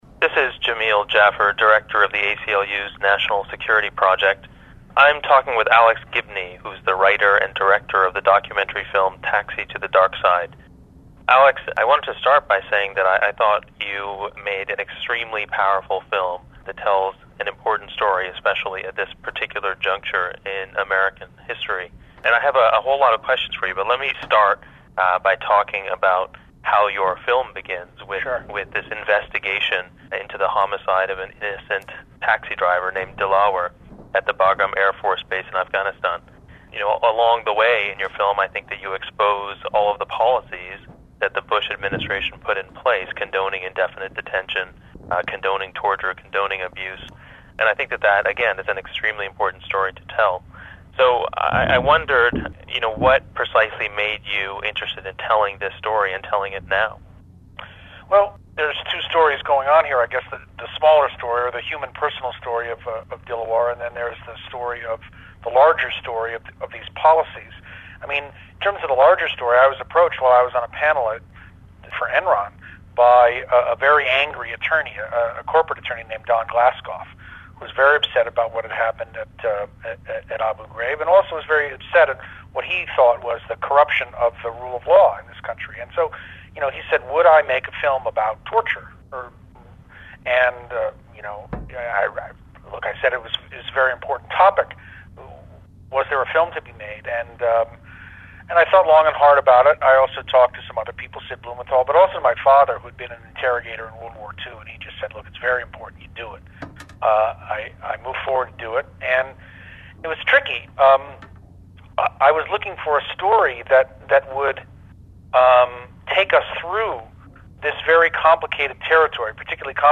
talks with filmmaker Alex Gibney, about his new documentary "Taxi to the Dark Side." streaming